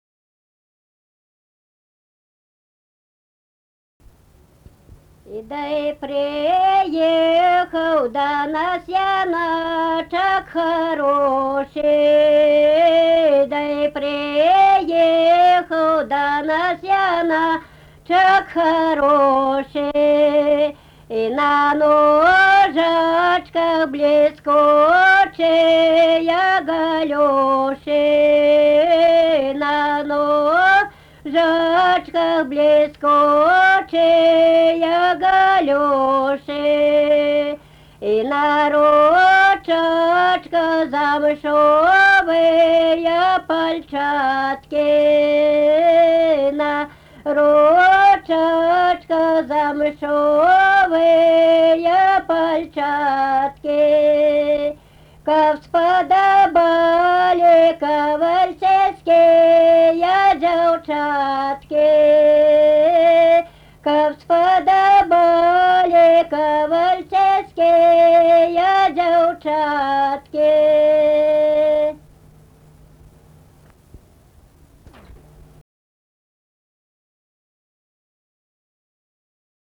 Kavaltiškė, Kavoliškės k.
Atlikimo pubūdis vokalinis
Baltarusiška daina